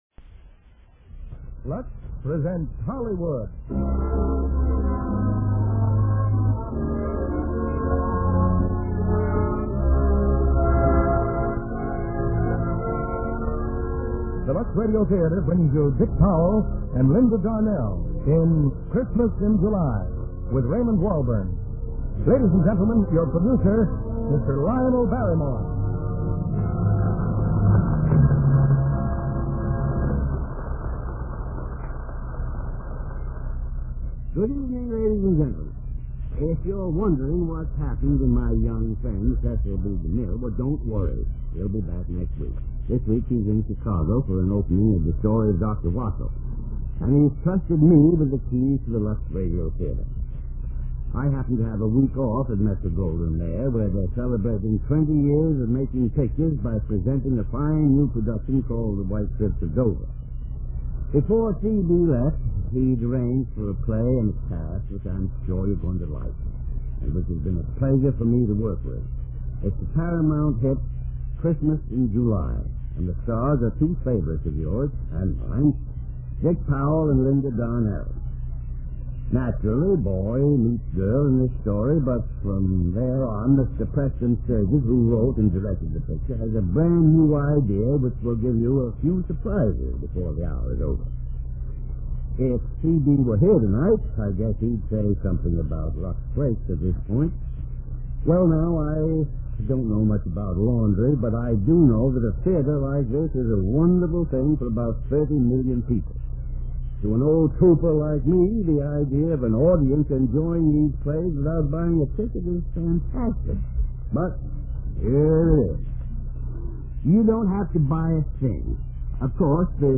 Lux Radio Theater Radio Show
Christmas in July, starring Dick Powell, Linda Darnell